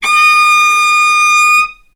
vc-D#6-ff.AIF